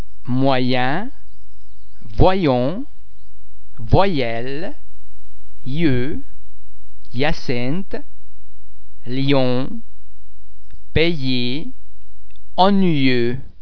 Please be mindful of the fact that all the French sounds are produced with greater facial, throat and other phonatory muscle tension than any English sound.
The French [ yeah ] sound is very much like the /y/ sound in the English words yes or eye.
·y
ille_moyen.mp3